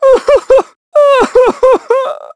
Evan-Vox_Sad.wav